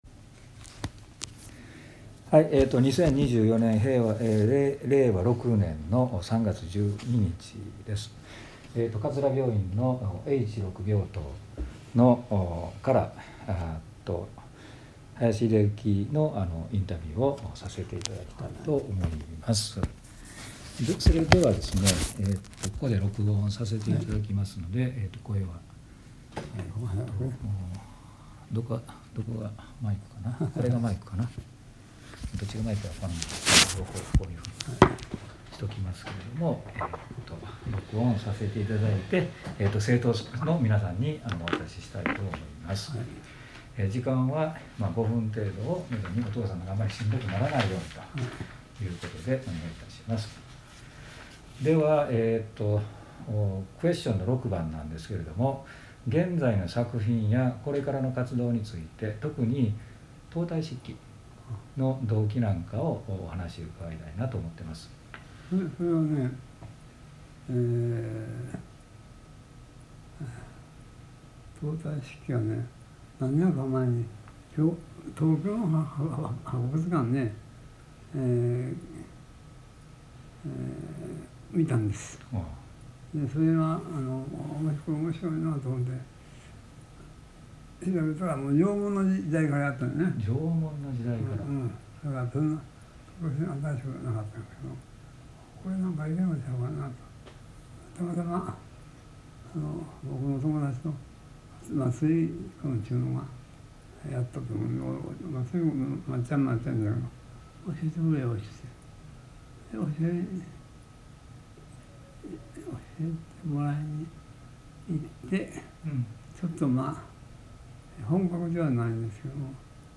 ご生前インタビュー